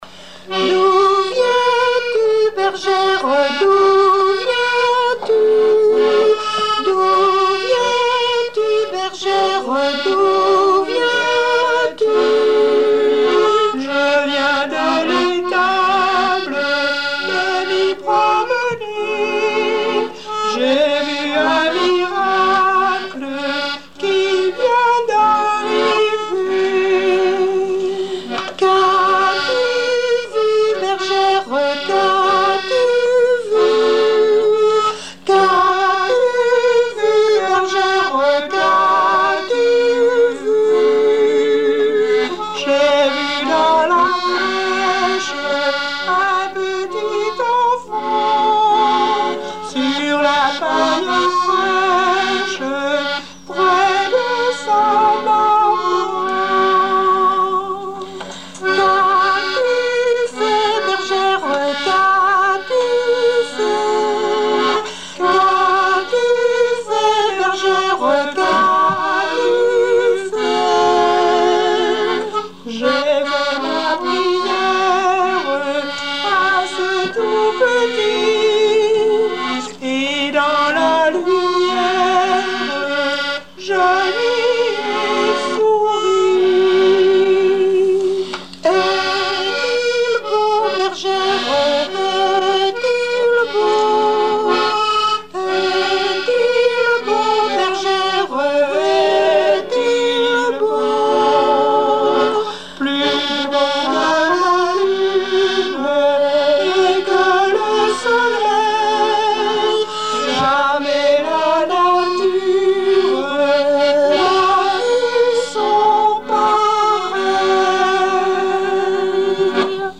Genre dialogue
émission La fin de la Rabinaïe sur Alouette
Pièce musicale inédite